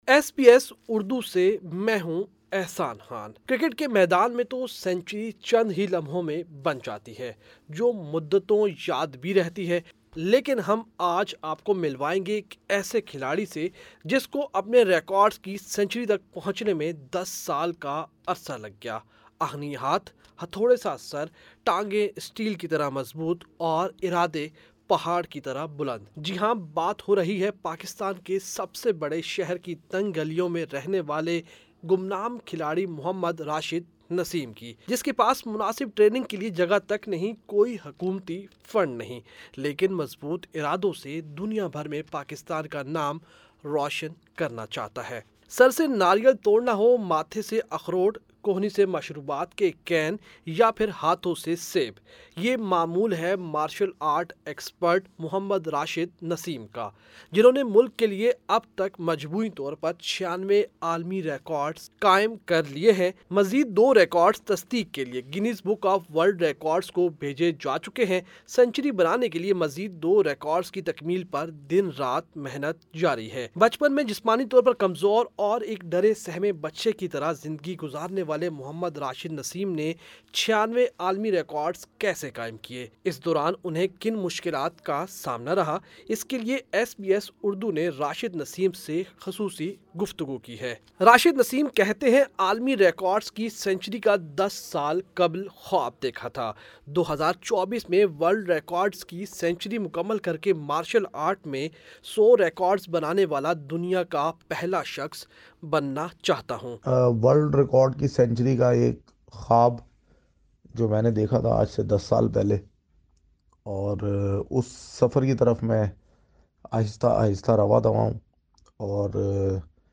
خصوصی گفتگو کی ہے۔